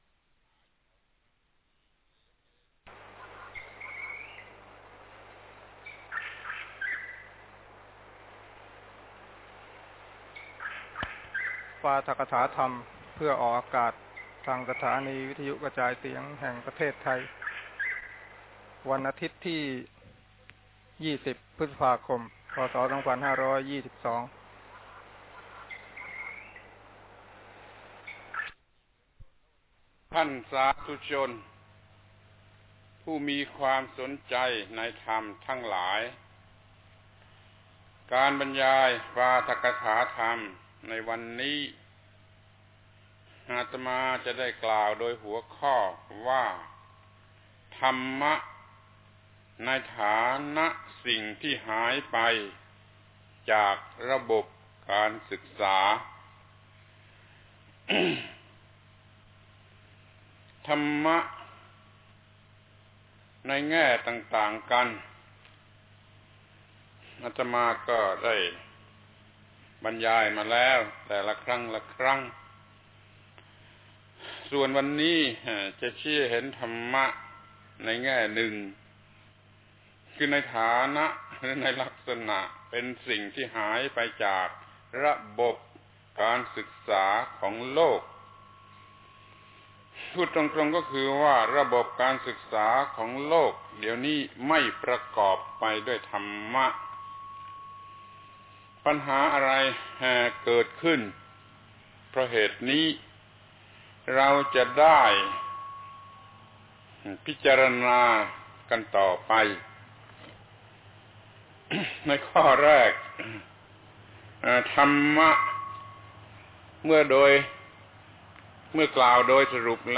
ปาฐกถาธรรมออกอากาศวิทยุประเทศไทย ศีลธรรมกลับมา ครั้งที่ 11 ธรรมะในฐานะสิ่งที่หายไปจากระบบการศึกษา